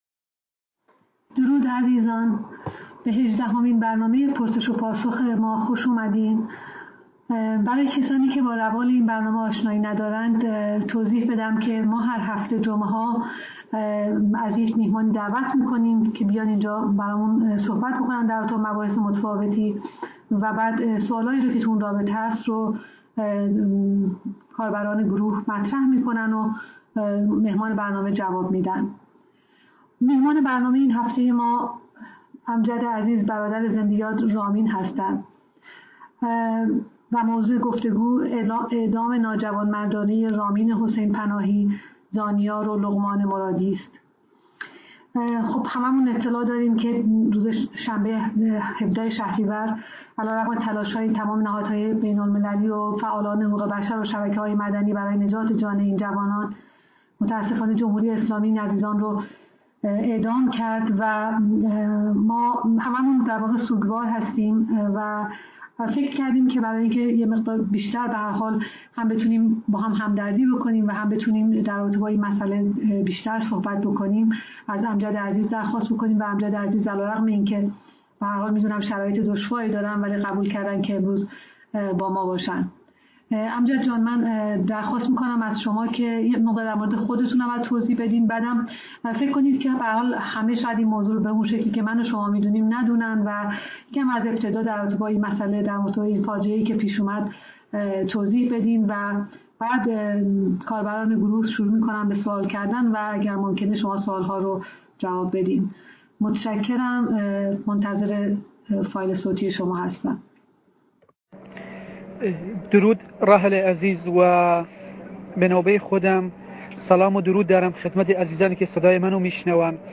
بحث و گفتگو
در تالار تلگرامی حمایت از زندانیان سیاسی و عقیدتی